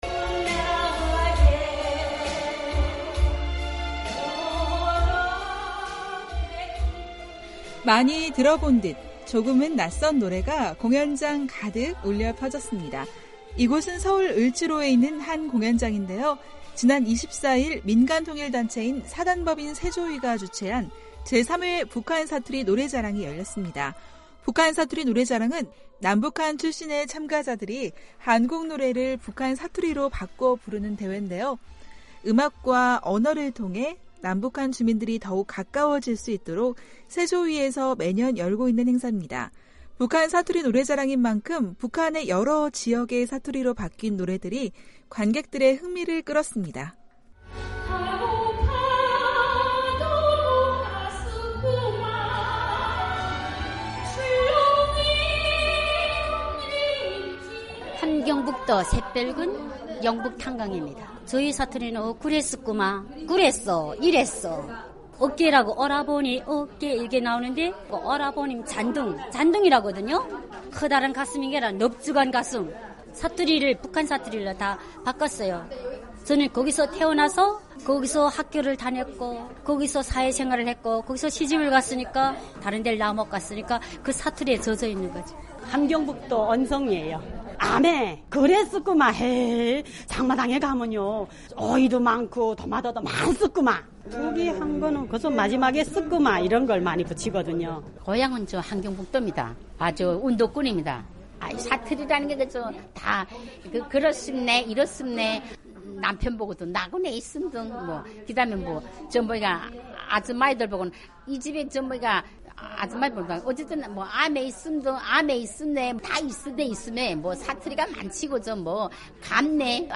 한국의 민간통일단체인 사단법인 새조위에서는 지난 2014년부터 매년, 한국의 노래를 북한의 사투리로 바꾸어 부르는 노래자랑을 열고 있습니다. 한반도 통일과 북한, 탈북민들과 관련한 한국 내 움직임을 살펴보는 ‘헬로 서울’ 입니다.